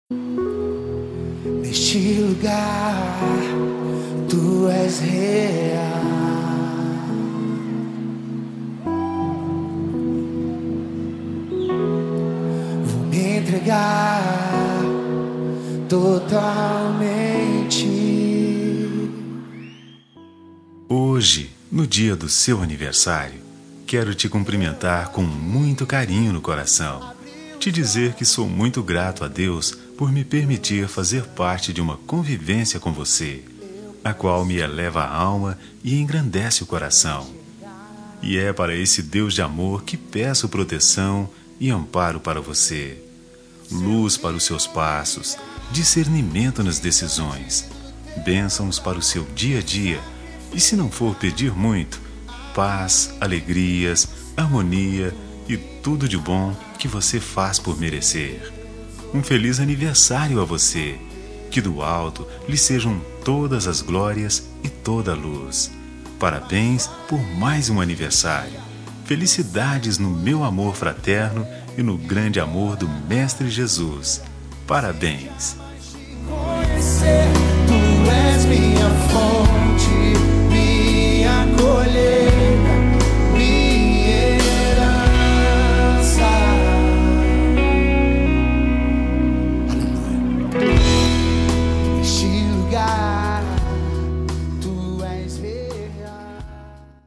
NEUTRA EVANGÉLICA
Voz Masculina